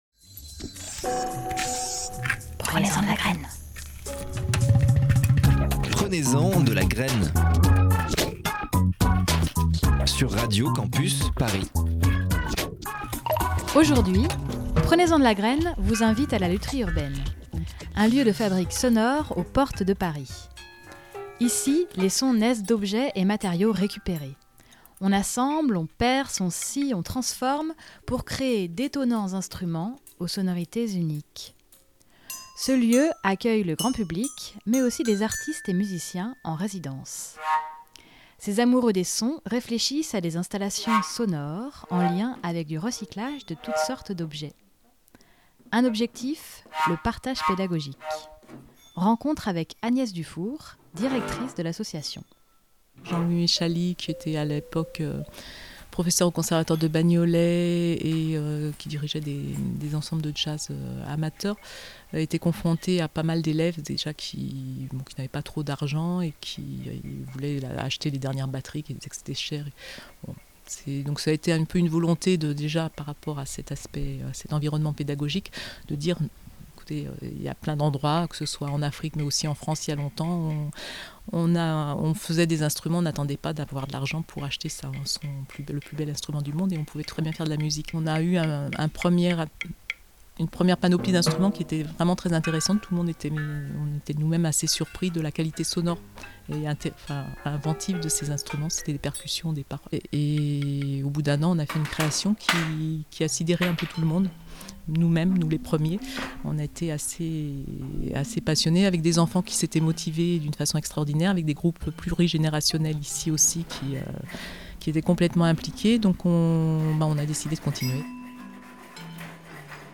Cette semaine, Prenez-en de la graine explore l'environnement sonore de la Lutherie Urbaine. Ce laboratoire des sons puise dans des matériaux récupérés pour fabriquer d'étonnants instruments.